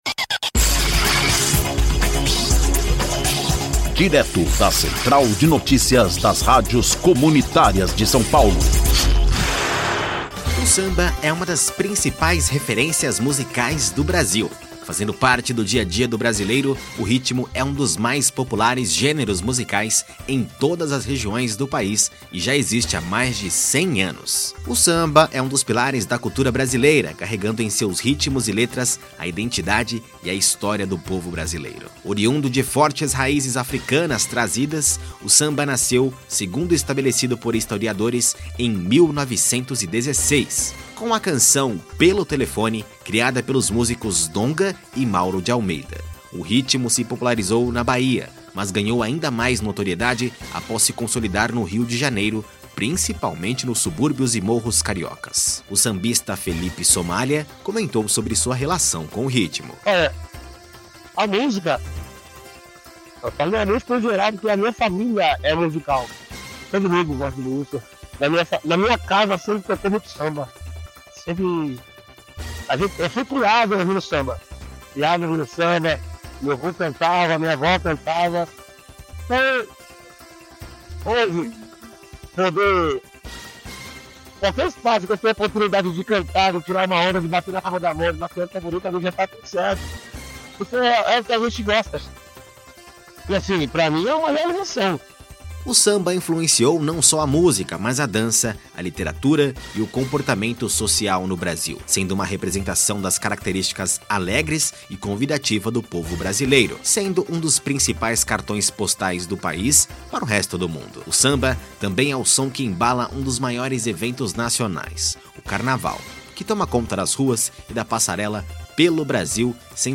Ouça a notícia: A Importância do Samba na Cultura Brasileira